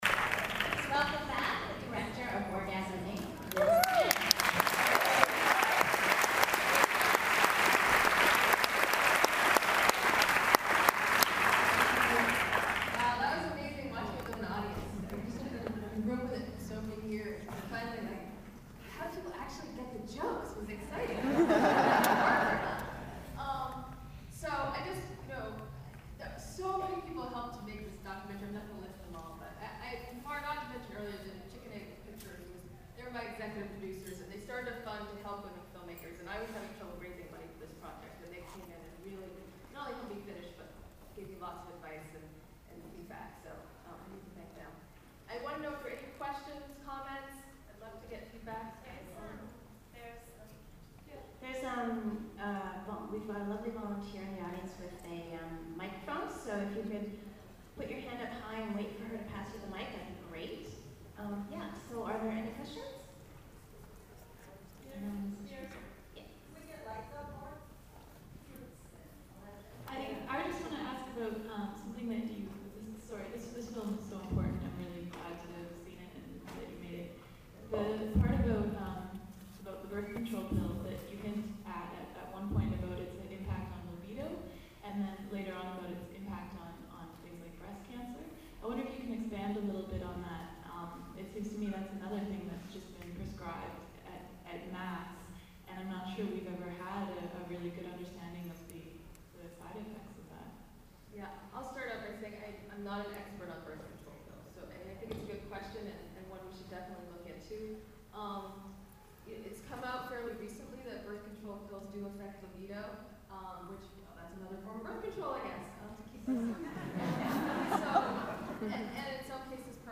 orgasminc_qa.mp3